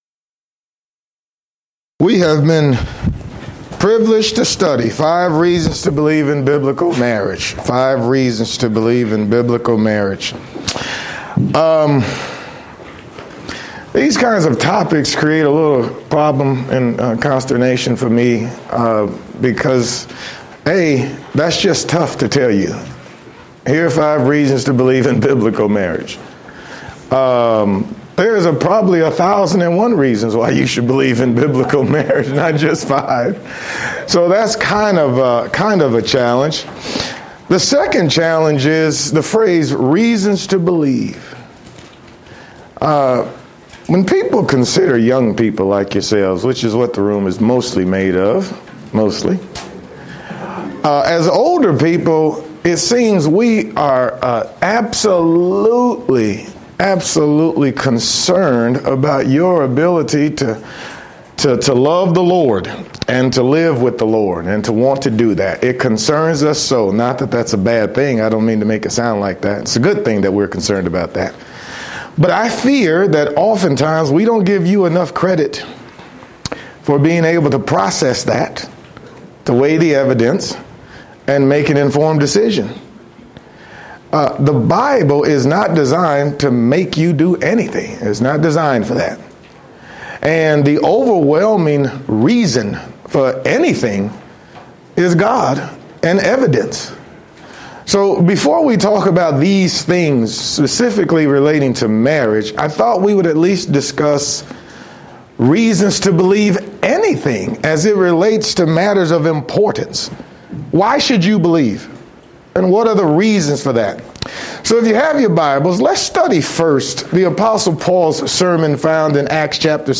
Youth Sessions